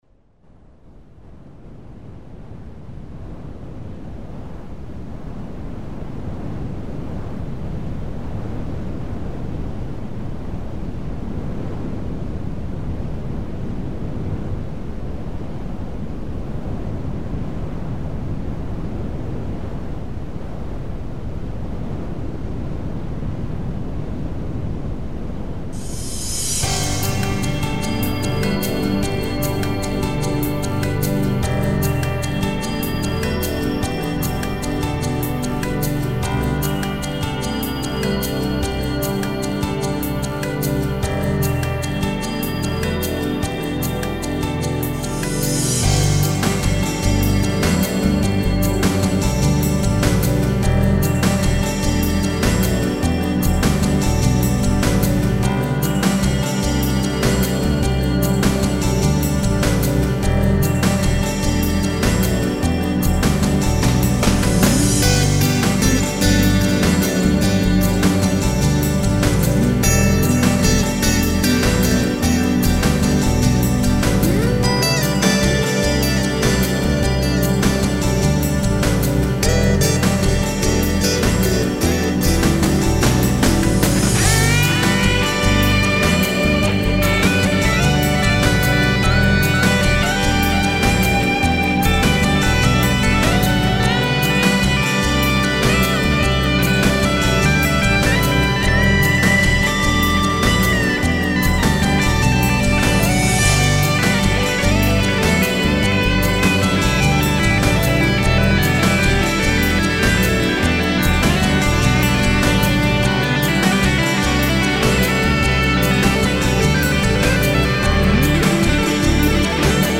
Scene #11 - Bending, tapping, bend & tap, tap & slide.
Scene #11 - More legato's and tapping.